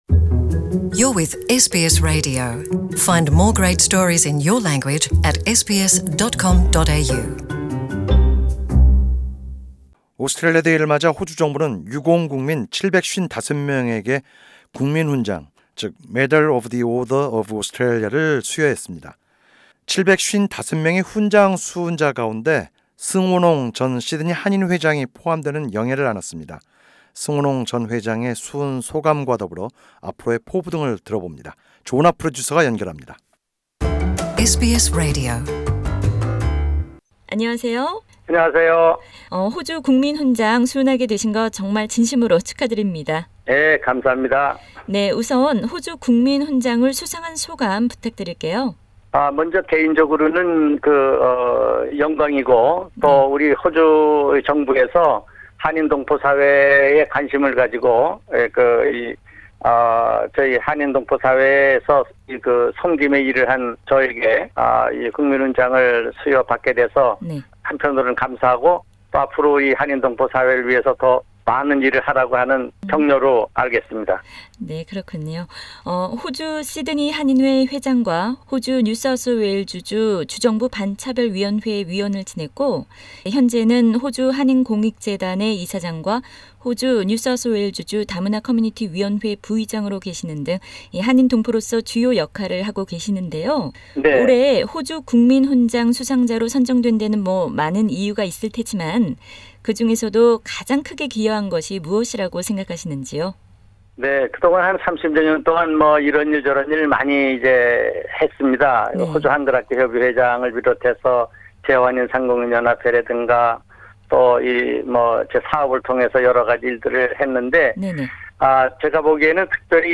[The full interview is available on the podcast above] Share